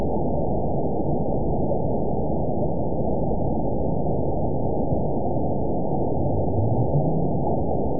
event 921007 date 04/24/24 time 00:33:10 GMT (1 year, 1 month ago) score 9.53 location TSS-AB02 detected by nrw target species NRW annotations +NRW Spectrogram: Frequency (kHz) vs. Time (s) audio not available .wav